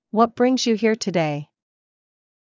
ﾜｯ ﾌﾞﾘﾝｸﾞｽ ﾕｳ ﾋｱ ﾄｩﾃﾞｨ